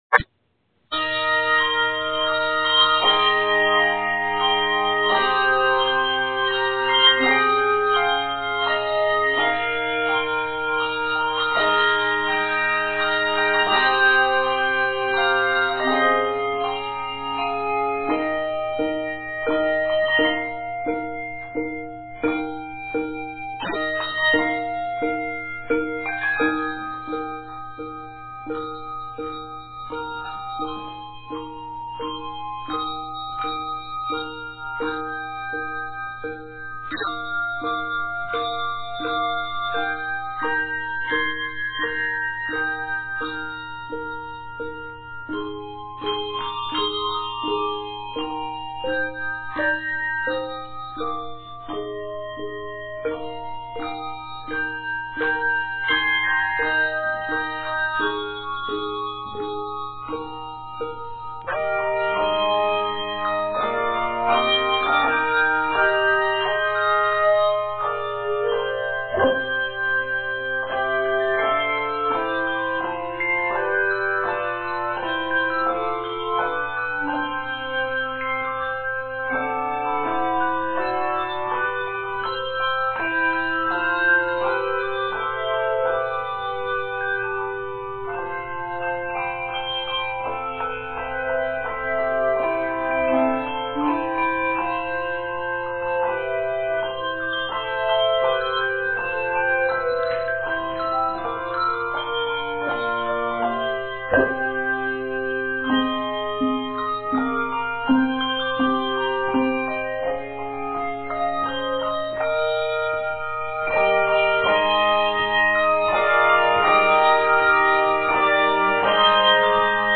this handbell piece
Octaves: 3-4